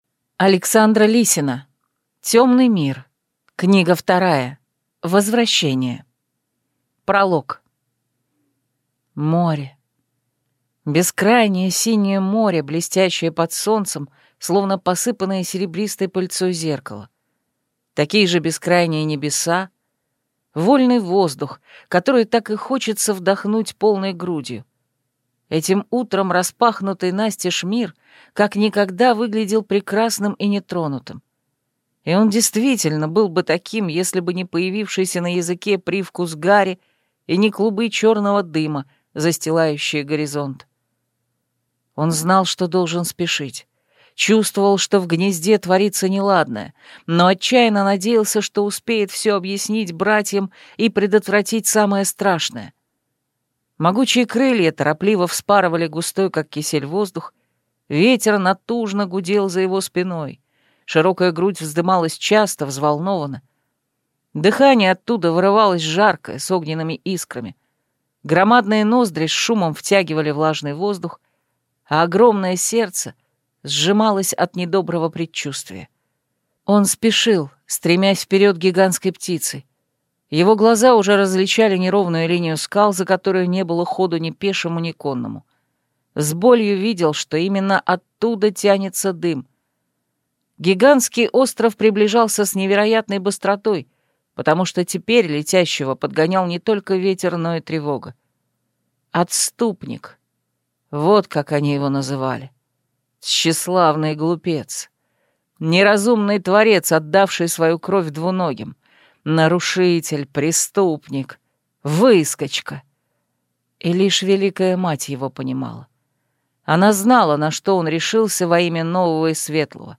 Аудиокнига Возвращение | Библиотека аудиокниг